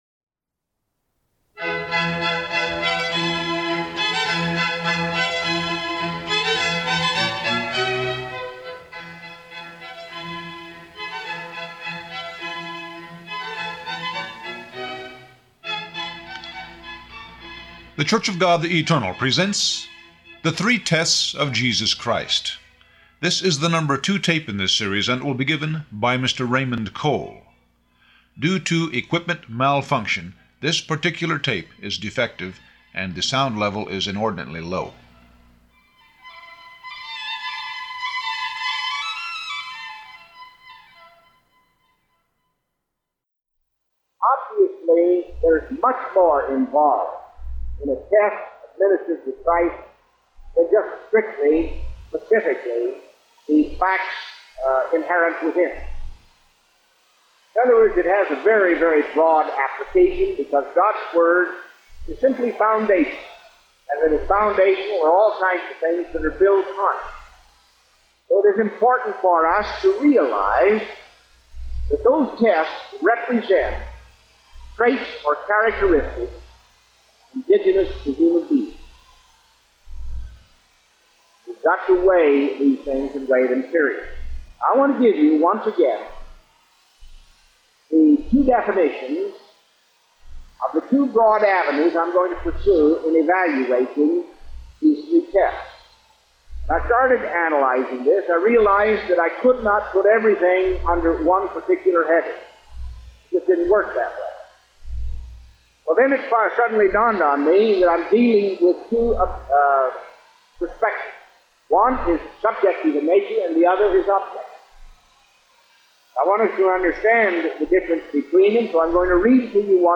This section catalogs weekly Sabbath sermons presented in Eugene, Oregon for the preceding twelve month period, beginning with the most recent.